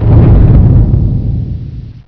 impact.wav